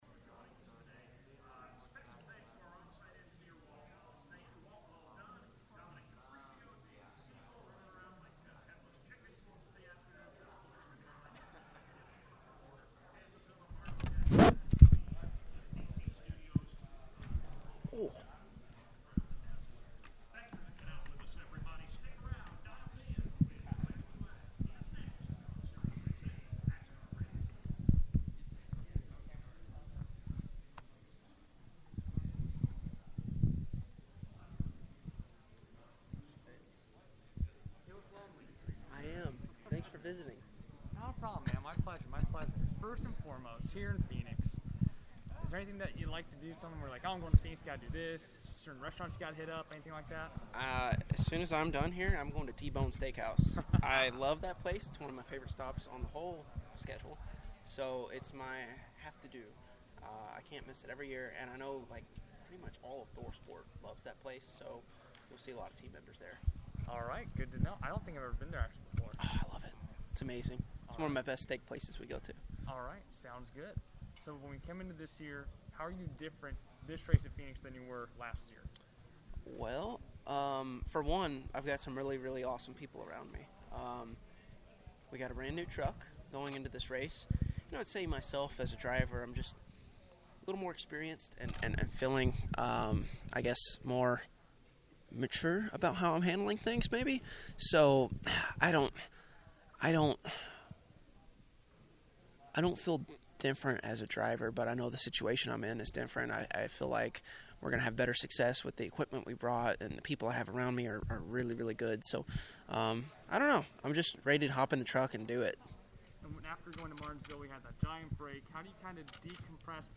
RhodesChamp4MediaDay.mp3